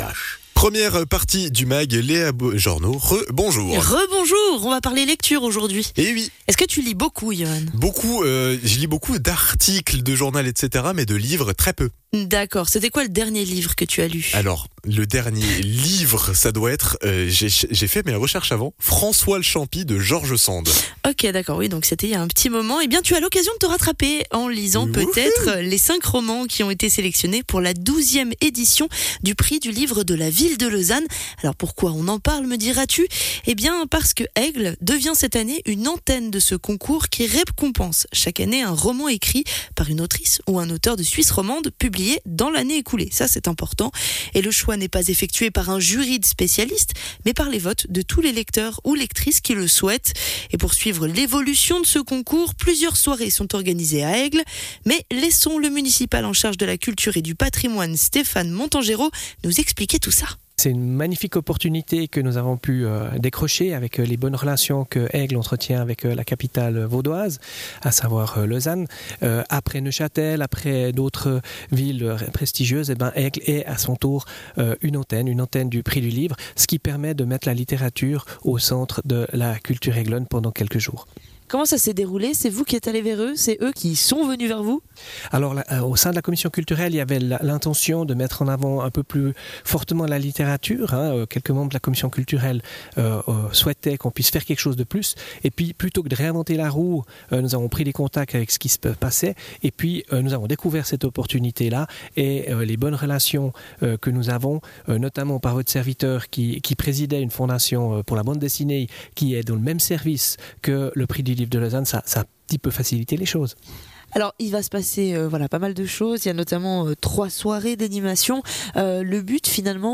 Intervenant(e) : Stéphane Montangero, municipal en charge de la culture et du patrimoine